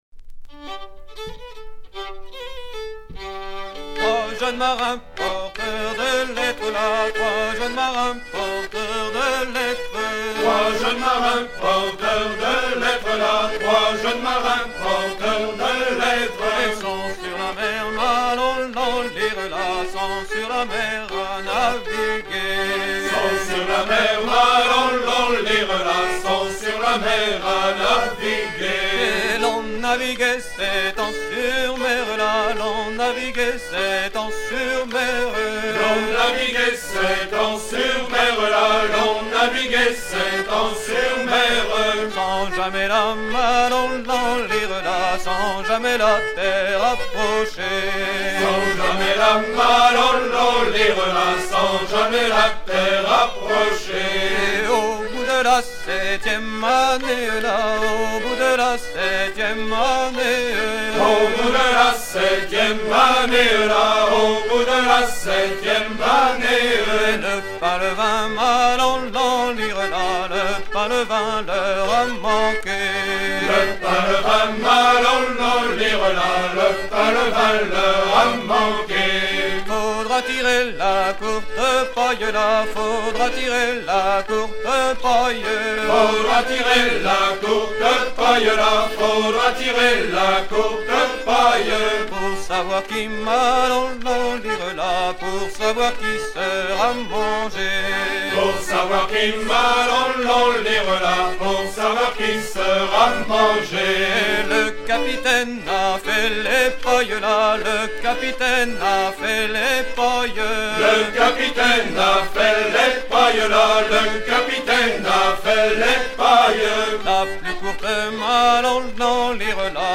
Version recueillie vers 1980 en presqu'île de Rhuys, servant à danser le tour
danse : an dro
Pièce musicale éditée